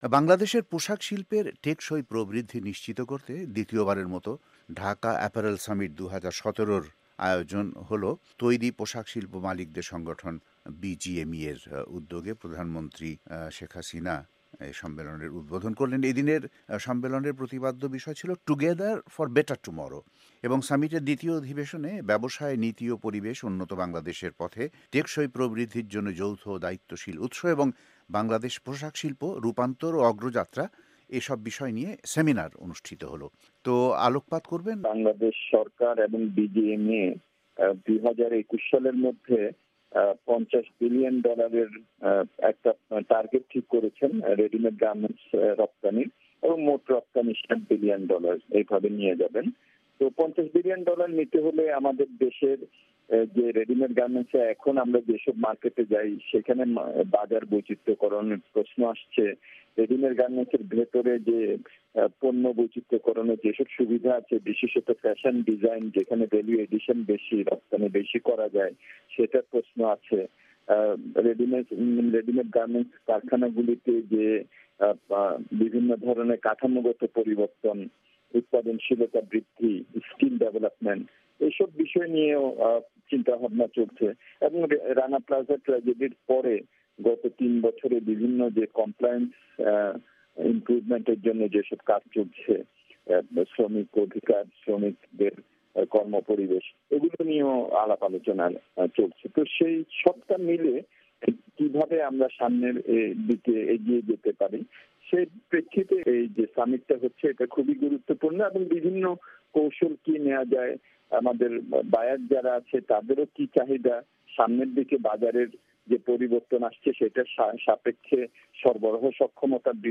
ওয়াশিংটন স্টুডিও থেকে